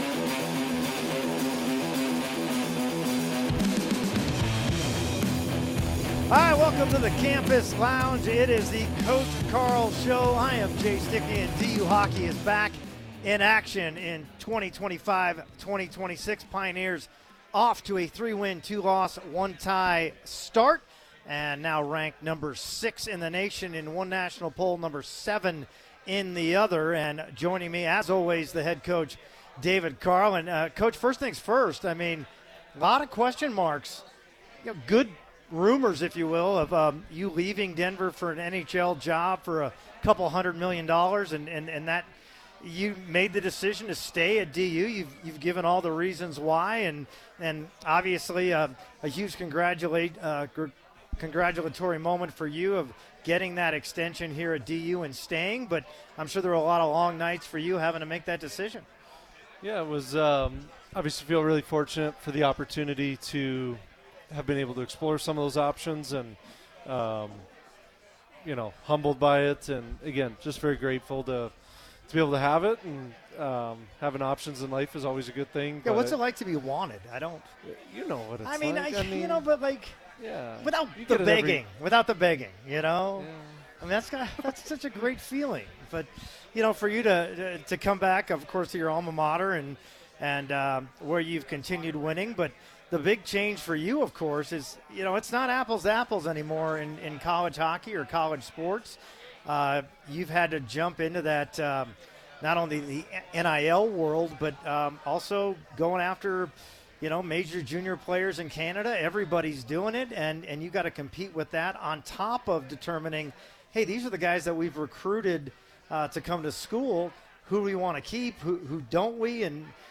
at the Campus Lounge for a live edition of the DU Coaches Radio Show